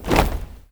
AFROFEET 1-L.wav